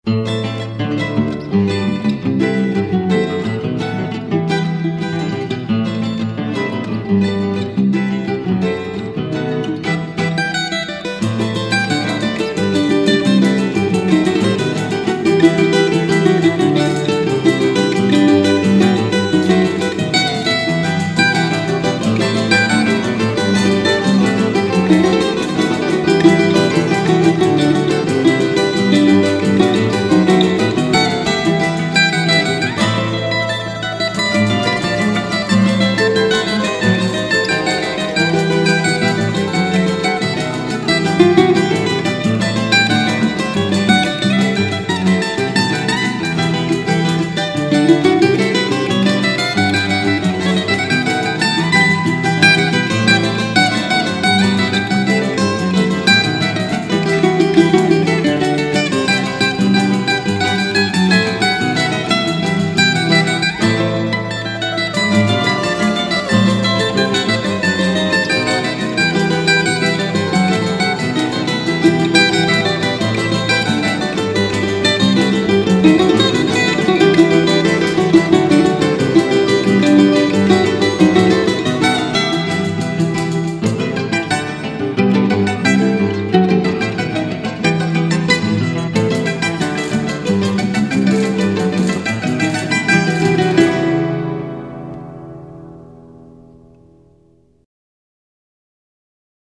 2915   01:39:00   Faixa: 3    Mpb